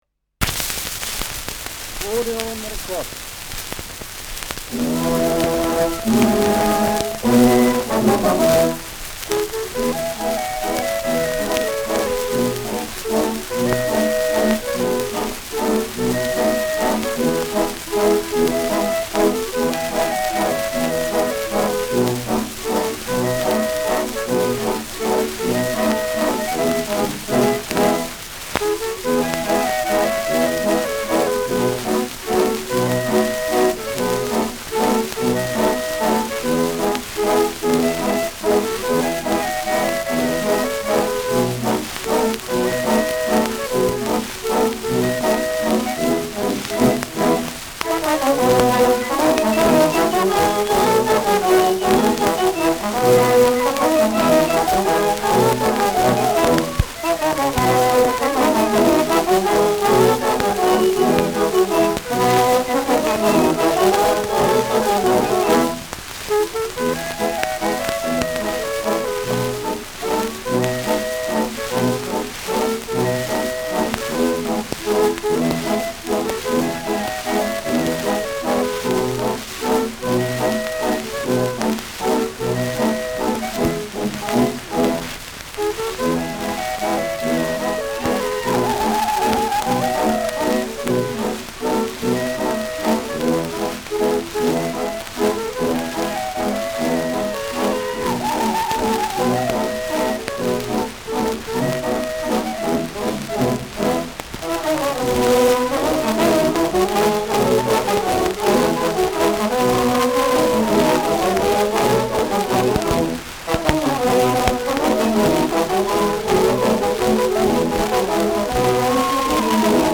Schellackplatte
präsentes Rauschen : präsentes Knistern : abgespielt : leiert : gelegentliches „Schnarren“ : gelegentliches Knacken
Militärmusik des k.b. 1. Infanterie-Regiments, München (Interpretation)
[Wien?] (Aufnahmeort)